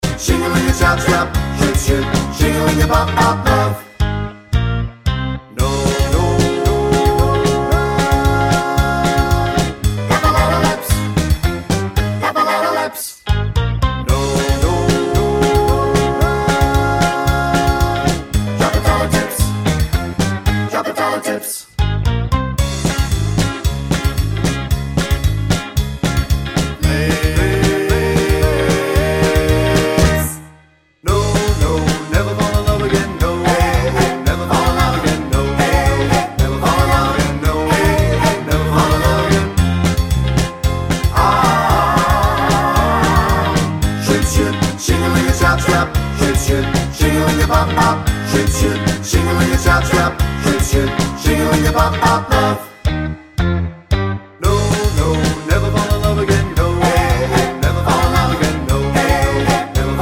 no Backing Vocals Rock 'n' Roll 2:16 Buy £1.50